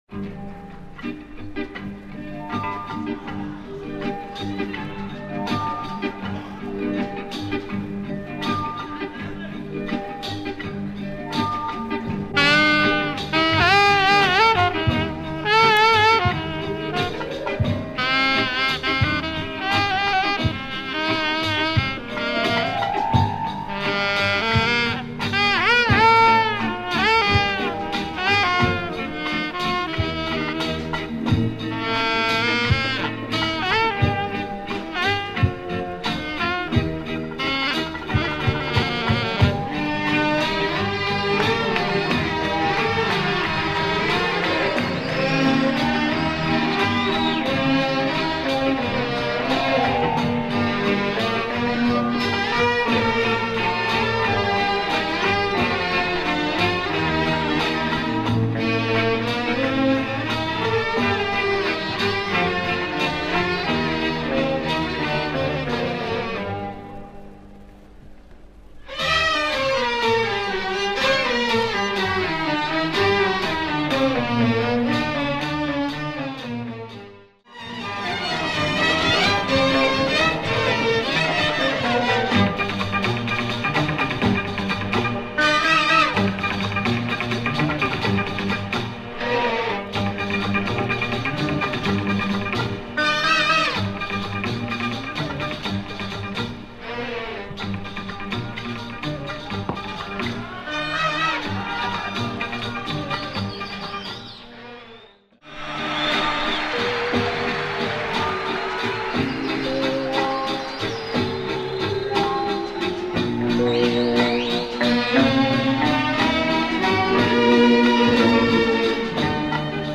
Wonderful oriental beats and samples !